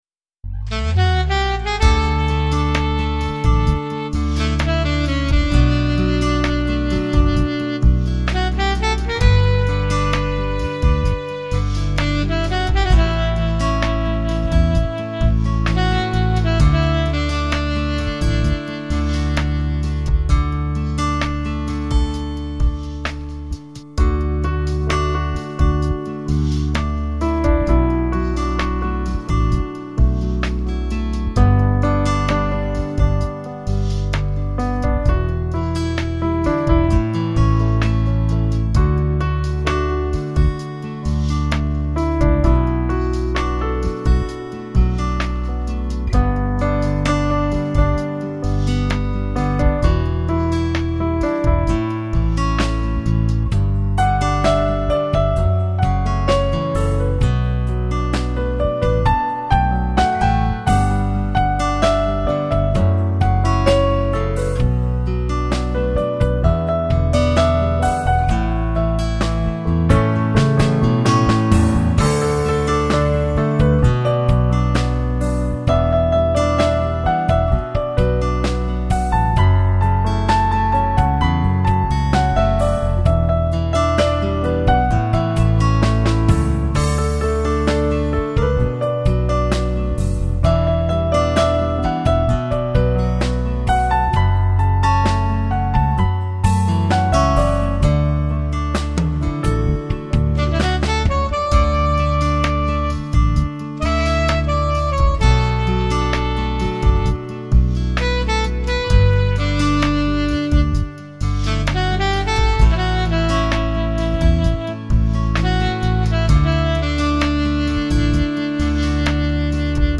钢琴演奏
钢琴可以更浪漫， 钢琴可以更简单，钢琴也可以不那么正经。
无论是最空灵的高音，抑或是最深沉的低音，你都可以触摸得到它无懈可击的音乐魅力！